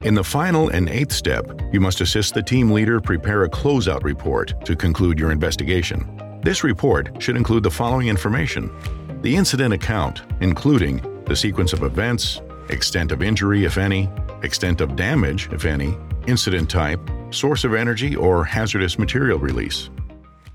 Commercieel, Opvallend, Veelzijdig, Warm, Zakelijk
Audiogids
He records from a professionally appointed home studio for clients across the globe.
His voice can be described as Warm, Deep, Authoritative, Calming, Confident, Authentic, Rustic, and Masculine.